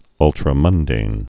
(ŭltrə-mŭndān, -mŭn-dān)